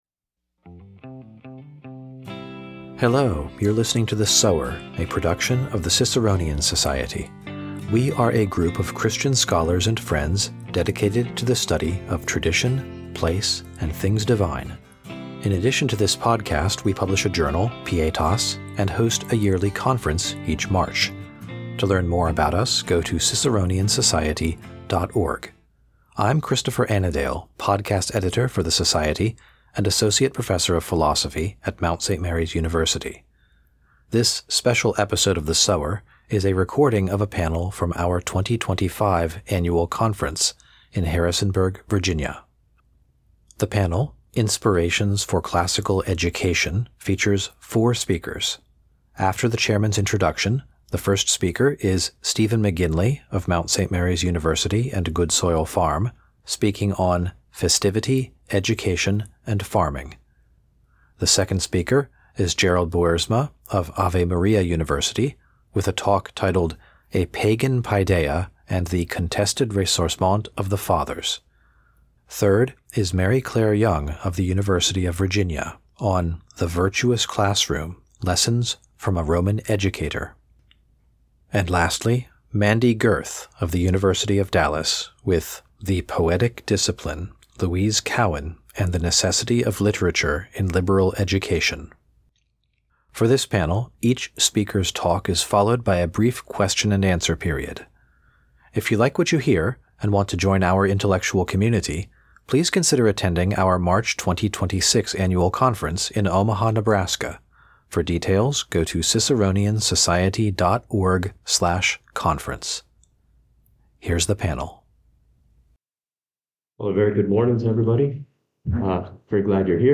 This special episode is a recording from our 2025 Conference in Harrisonburg, Virginia.